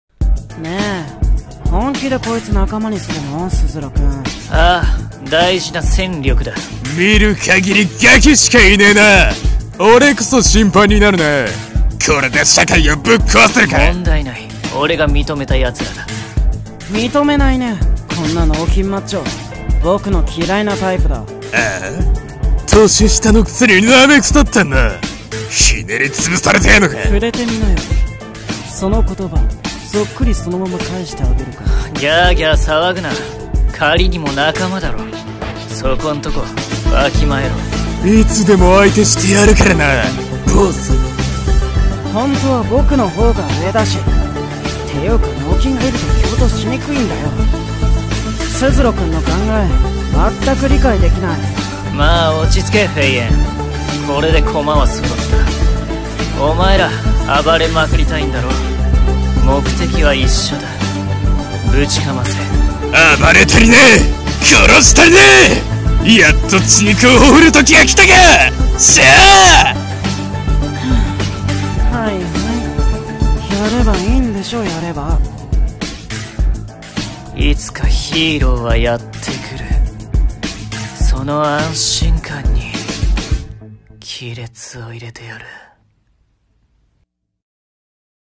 声劇】カルネージの鬼、見参